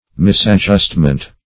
Misadjustment \Mis`ad*just"ment\, n. Wrong adjustment; unsuitable arrangement.
misadjustment.mp3